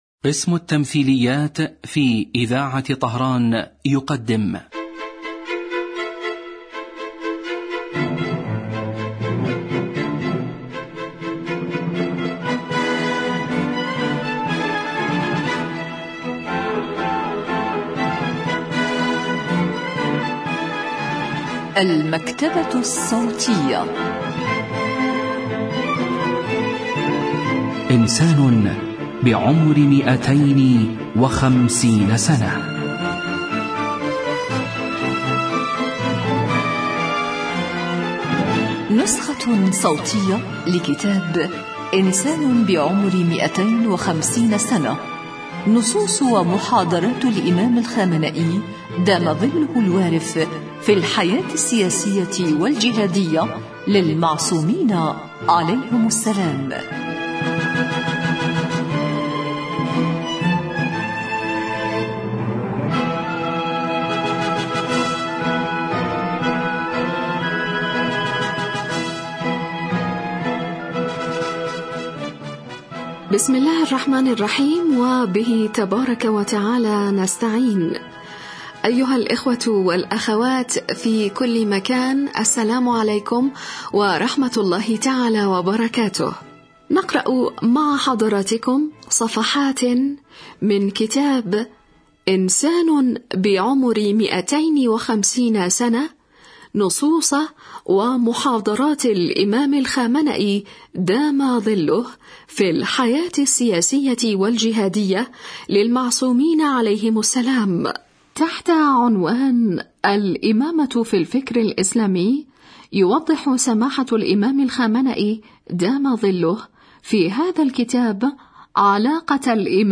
إذاعة طهران- إنسان بعمر 250 سنة: نسخة صوتية لكتاب إنسان بعمر 250 سنة للسيد علي الخامنئي في الحياة السياسية والجهادية للمعصومين عليهم السلام.